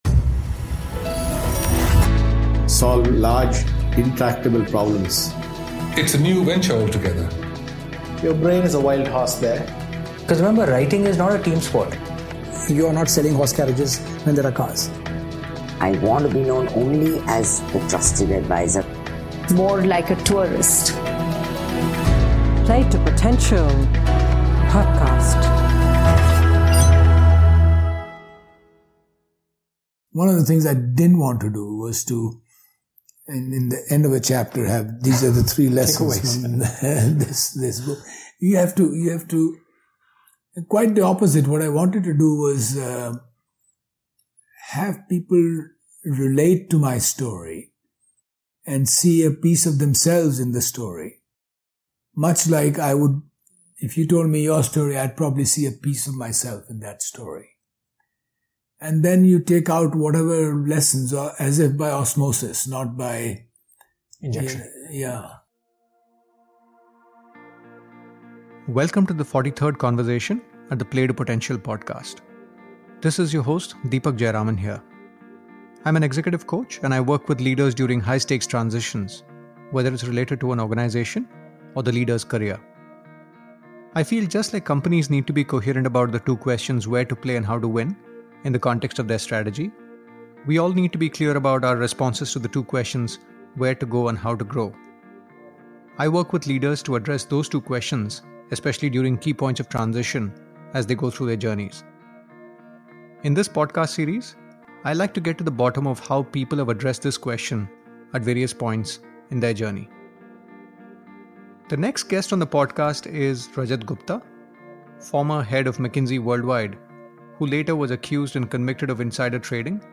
ABOUT THE PODCAST Play to Potential podcast started in Dec 2016 and features conversations around three broad themes - Leadership, Transitions and Careers.